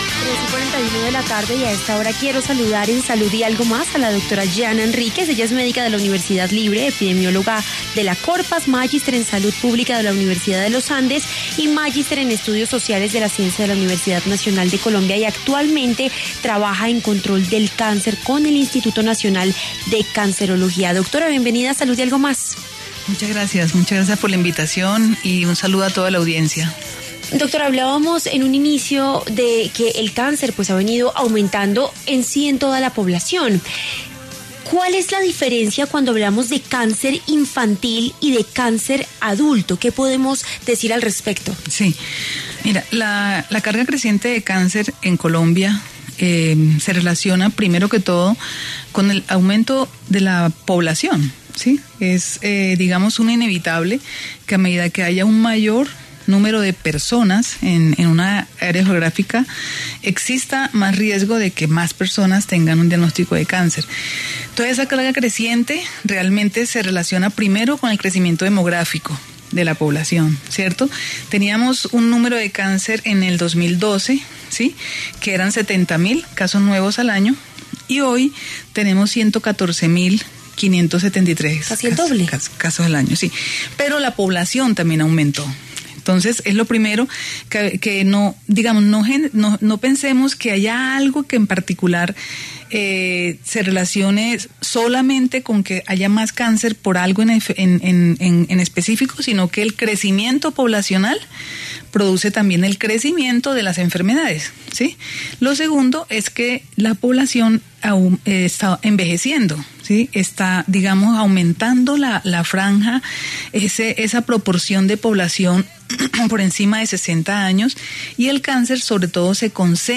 ¿Cómo prevenir el cáncer? Médica explica los principales factores de riesgo